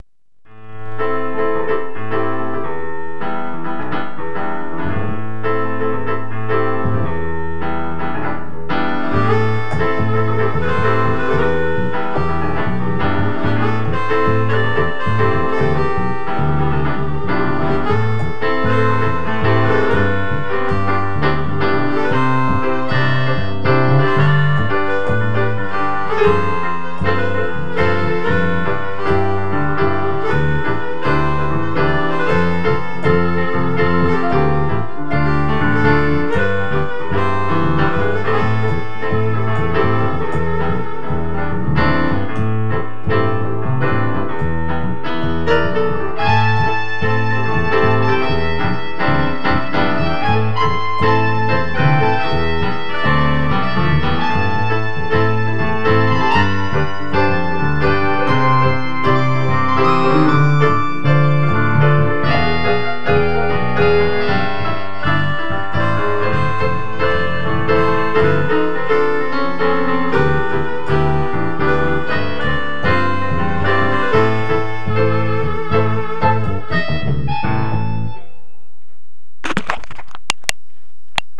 סקסופון/חצוצרה: האורגן שלי